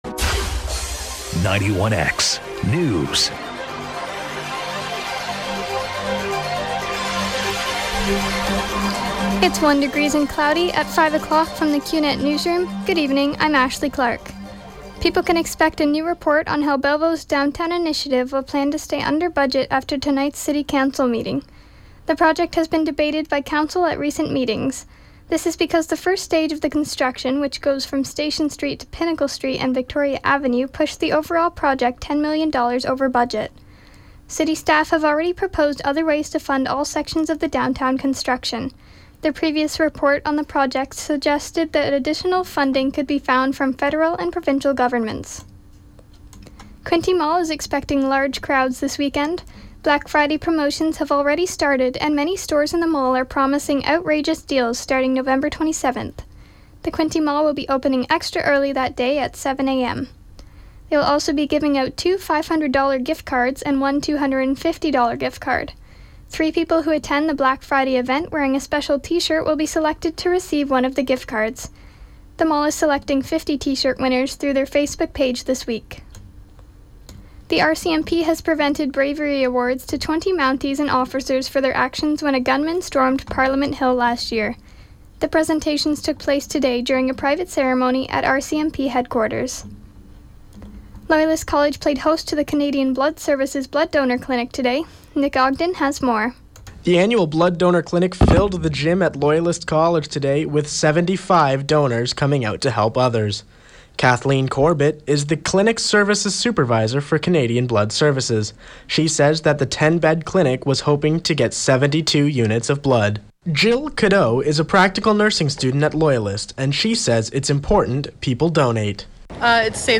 91X newscast – Monday, Nov. 23, 2015 – 5 p.m.